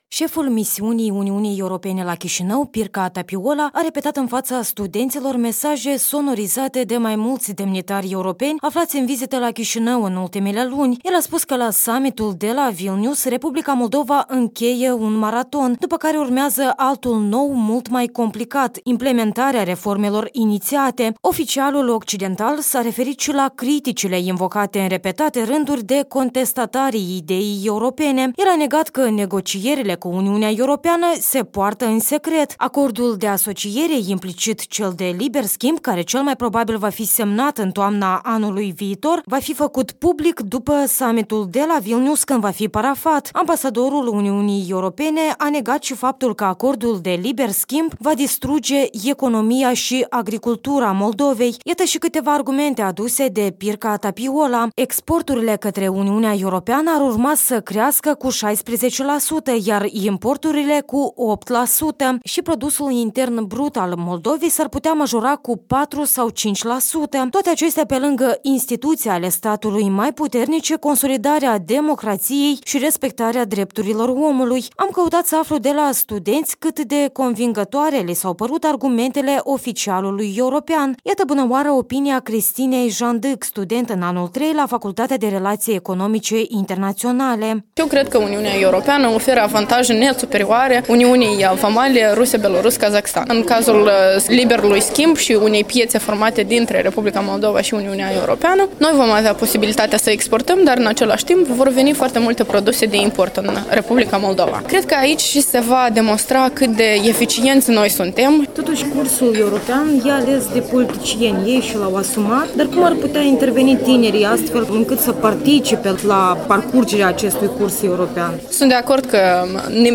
Iurie Leancă şi Pirkka Tapiola într-o dezbatere publică la ASEM